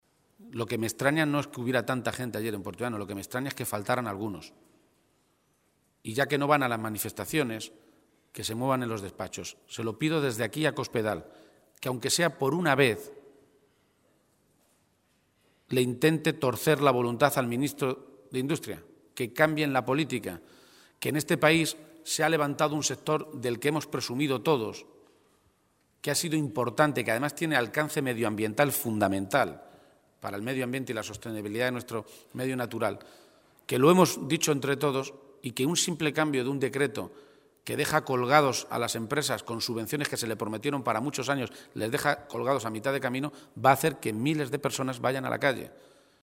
García-Page se pronunciaba de esta manera esta mañana, en Toledo, a preguntas de los medios de comunicación sobre la extraordinaria movilización vivida ayer en Puertollano contra el cierre de Elcogás, en una manifestación que congregó a más de 20.000 personas, según los sindicatos convocantes, y en la que participó también el propio líder de los socialistas castellano-manchegos.
Cortes de audio de la rueda de prensa